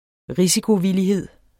Udtale [ ˈʁisikoviliˌheðˀ ]